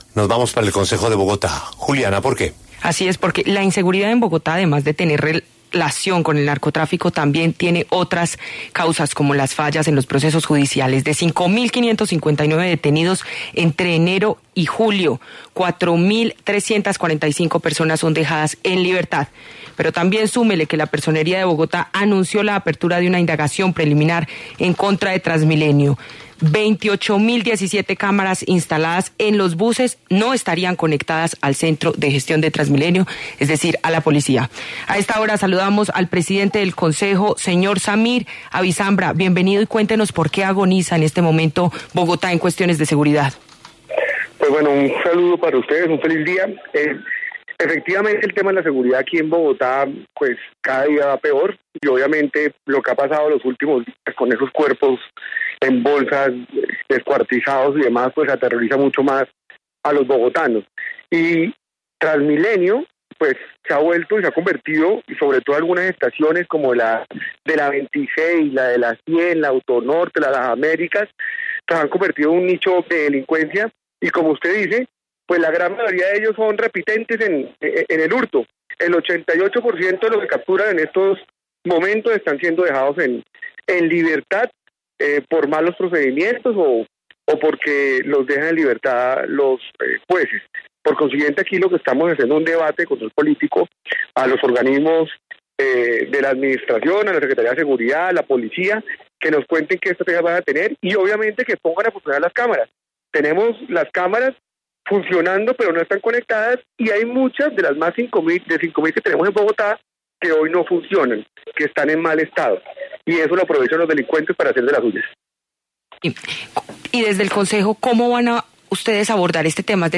Frente a este anuncio, el presidente del Concejo de Bogotá, Samir Abisambra, aseguró en los micrófonos de La W que “el tema de la seguridad en la ciudad cada día va peor”.